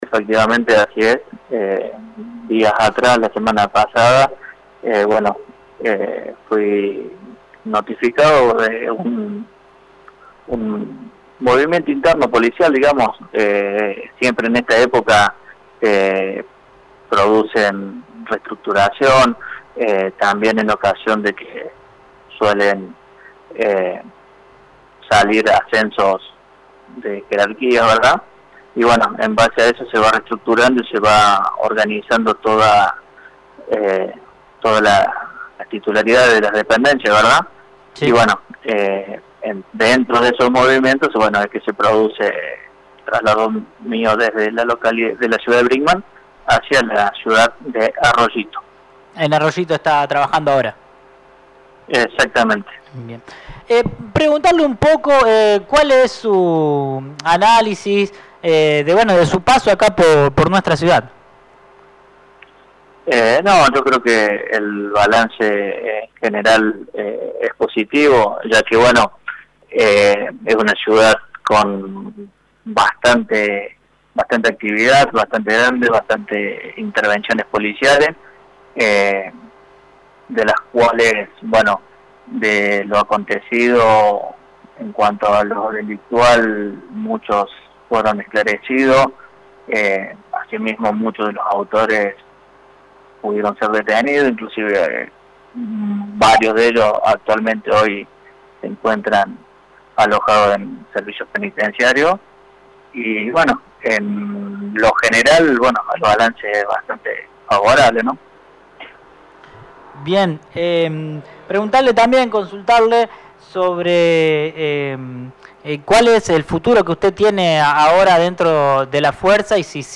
habló en LA MAÑANA DE LA RADIO